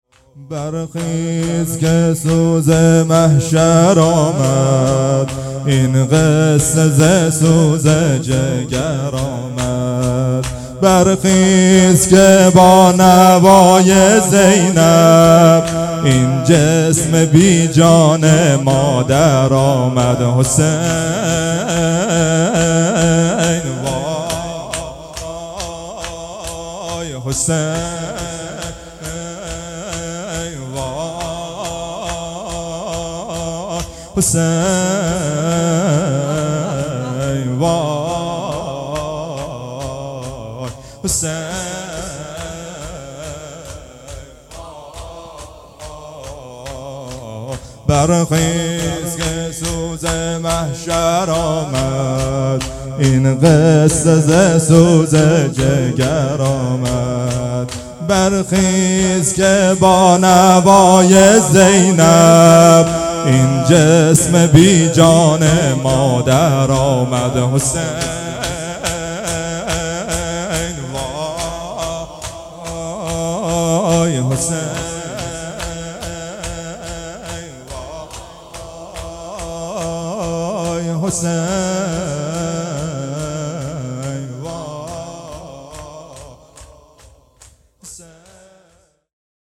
اقامه عزای شهادت حضرت زهرا سلام الله علیها _ دهه دوم فاطمیه _ شب اول